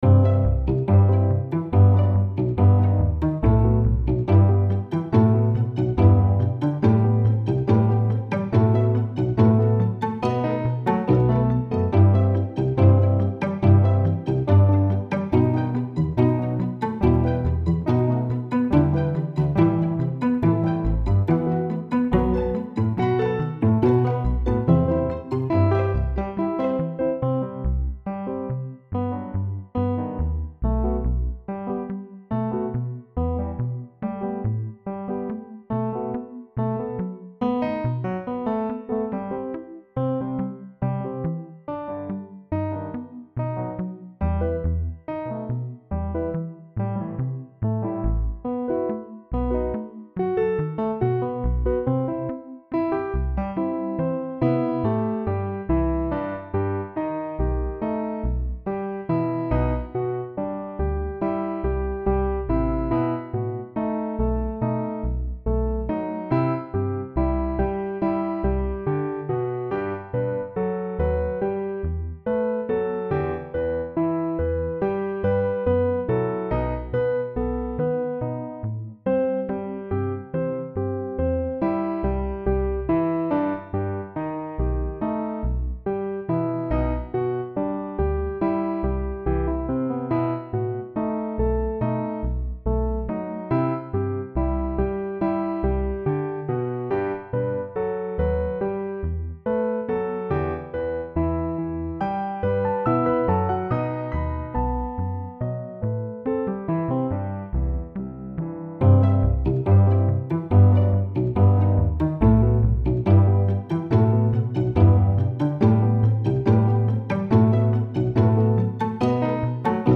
PianoTeq 1 (piano)
Scorpion (bass)
Sampletank 2 (strings).
This song makes extensive use of Metro's Rhythm Explorer, hence the name.
(However, the extreme quantization makes the piano sound very unrealistic and "MIDI-esque" in some parts of the song. A little "Human Feel" applied after the Rhythm Explorer wouldn't hurt.)
Kind of hypnotic, in a way.
Sounds "classical" (Mozart/Bach). Nice ending fade.